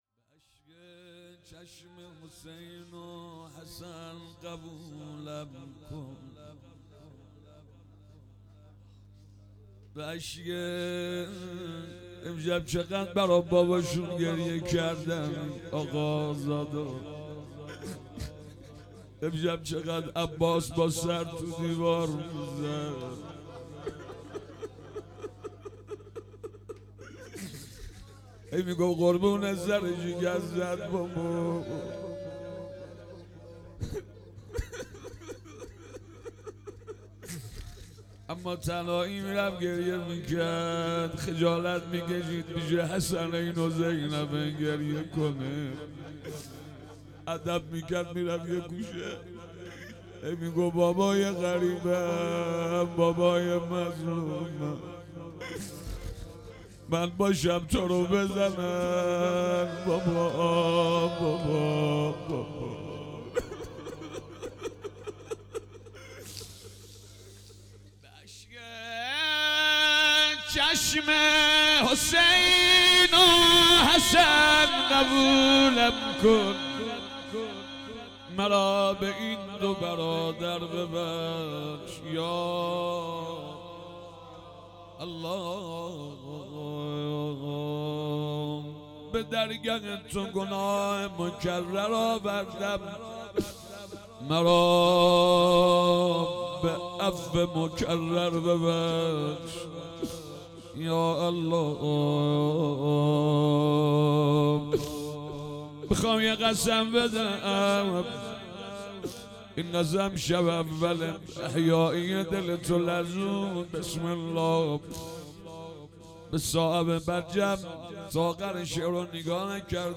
هیئت حضرت ابوالفضل(ع)ورامین - شب های قدرشهادت حضرت علی(ع)روضه